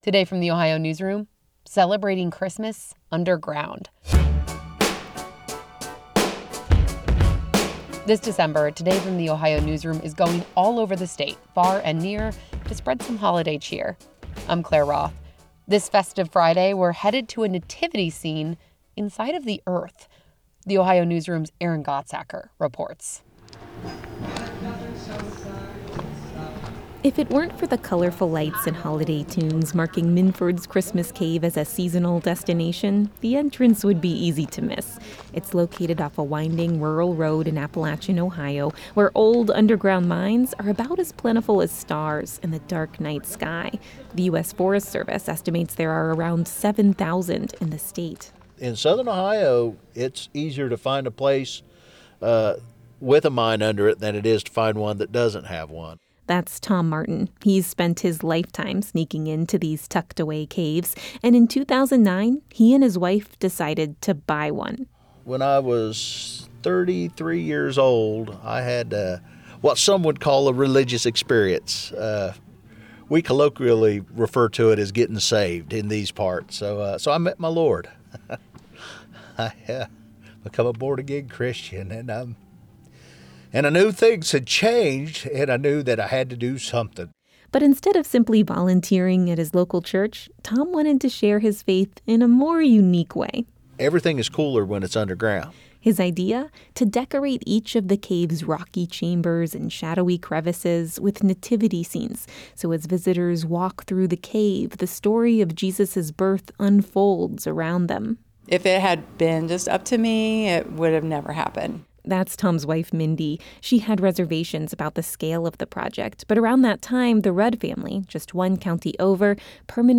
The mouth of the Christmas Cave is cold and dark, but soft Christmas music beckons visitors inside.
Further into the cave’s shadowy passageway, the music picks up in intensity.